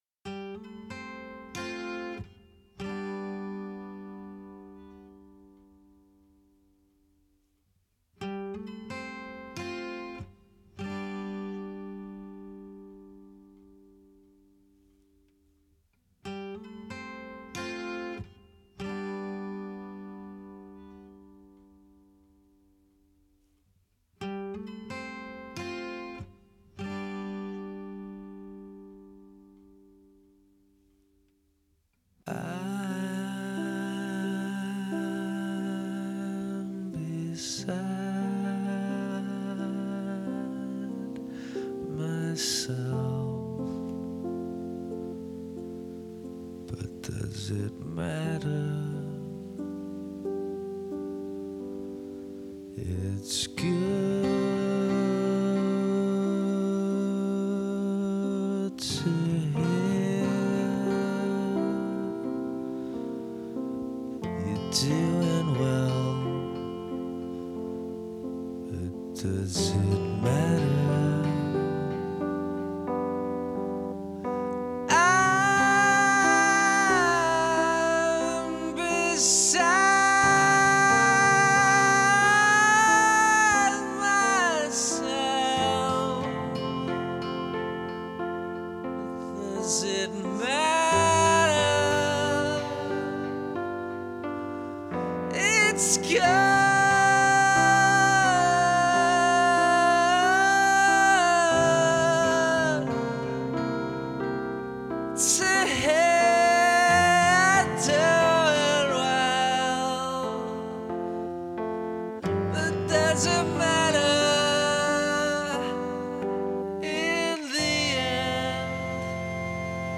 Alternative Rock